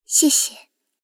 追猎者补给语音.OGG